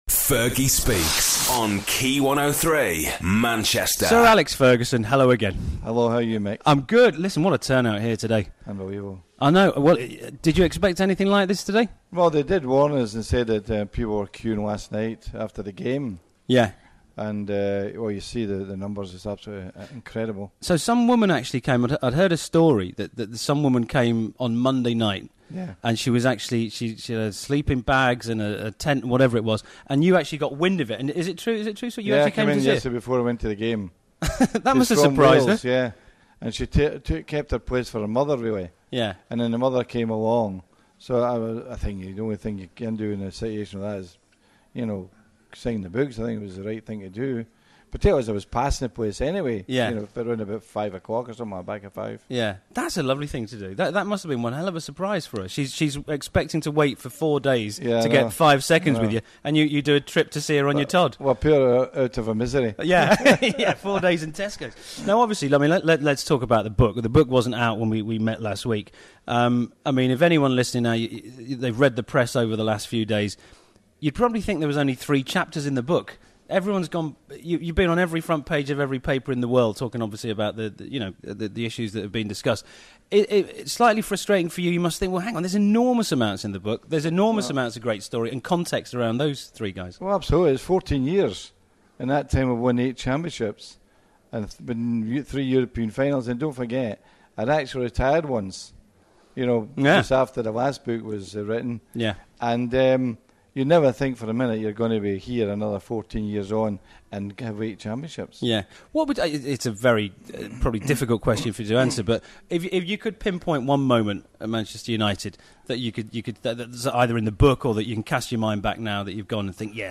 Sir Alex Ferguson Talks to Key 103 Part 5